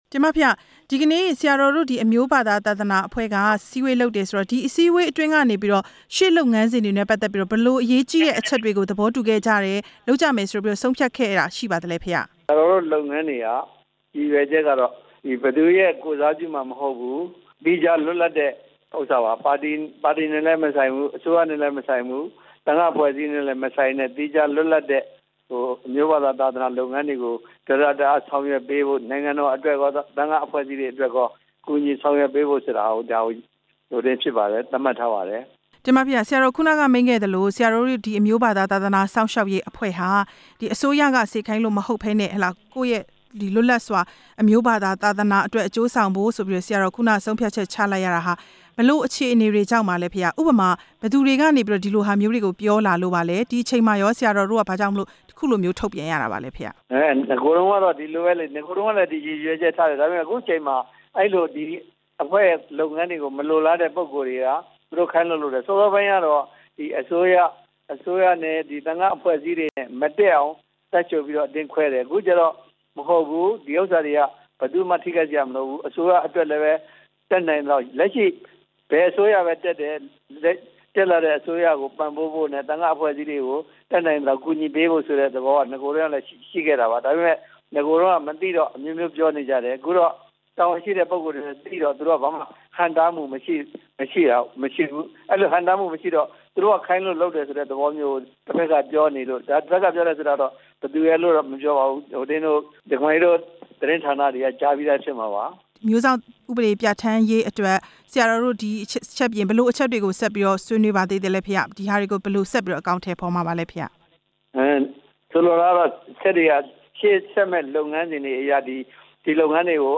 မိန့်ကြားချက်